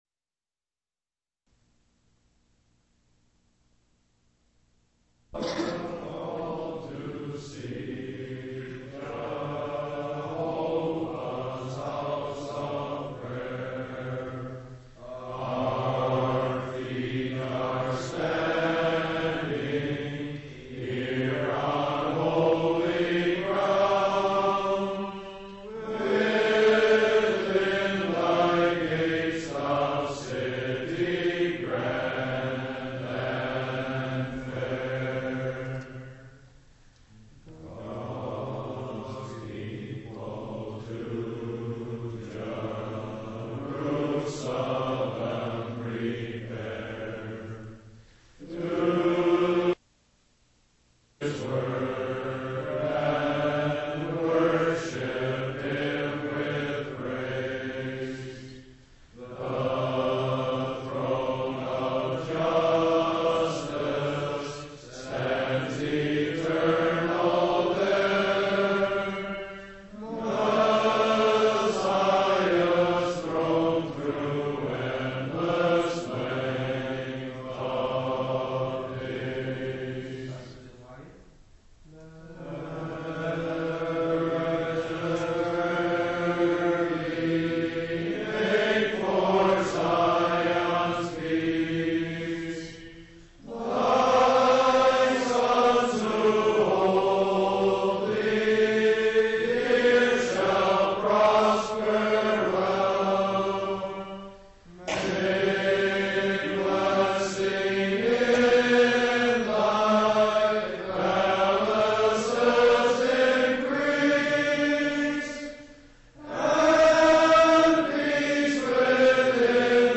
Office Bearer's Conference